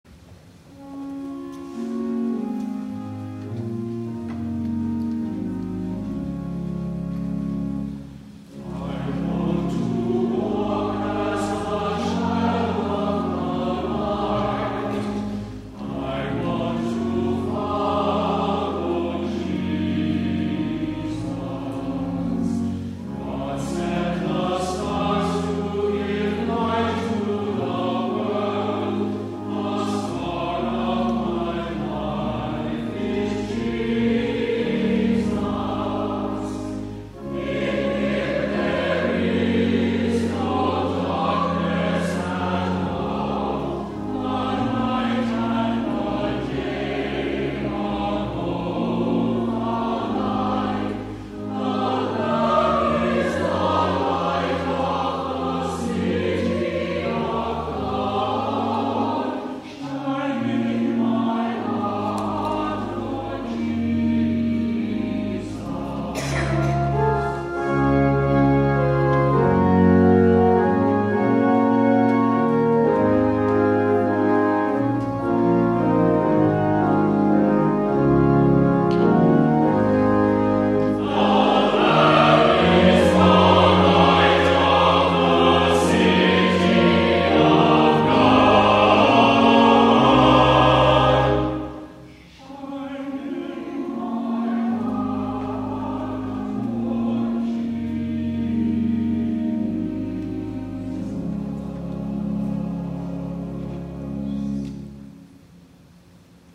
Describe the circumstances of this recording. WORSHIP FEBRUARY 9, 2014